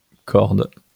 wymowa:
IPA/kɔʁd/ ?/i